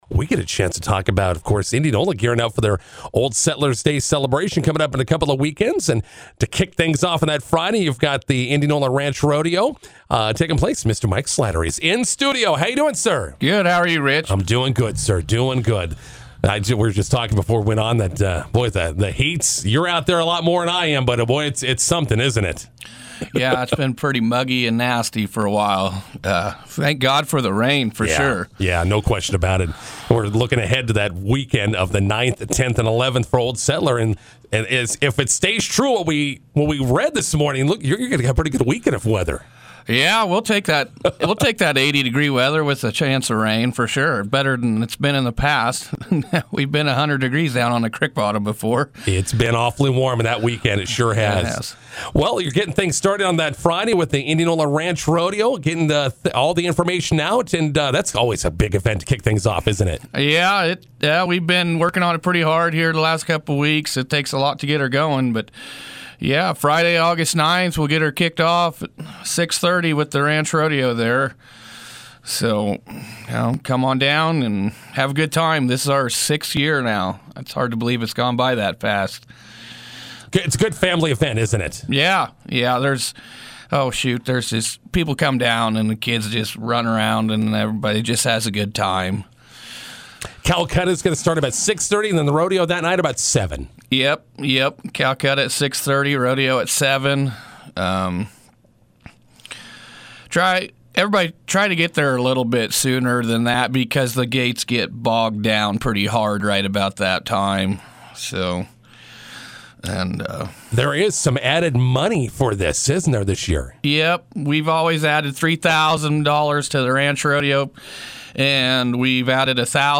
INTERVIEW: Old Settlers Day weekend kicks off with Indianola Ranch Rodeo.